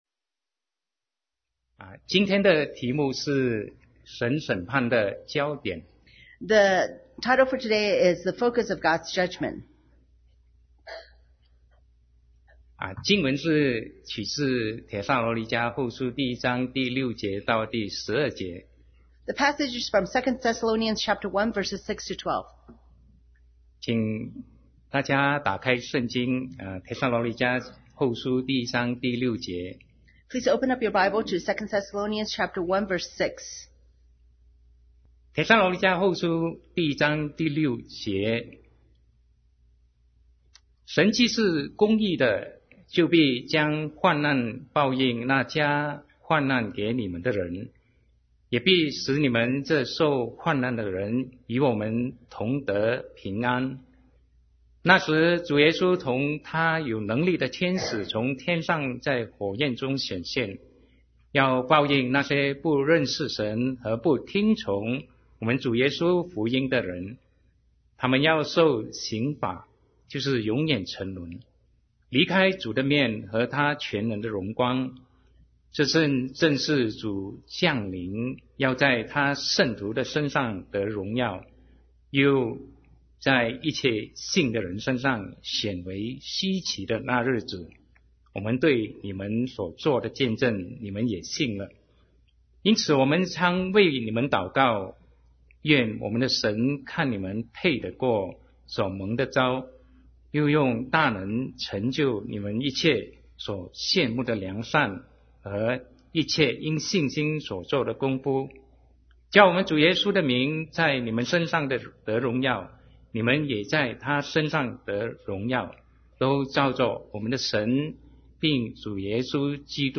Sermon 2017-09-10 The Focus of God’s Judgment